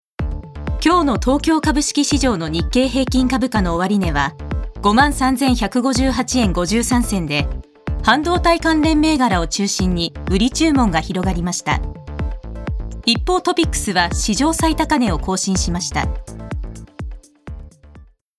Voice／メゾソプラノ
ボイスサンプル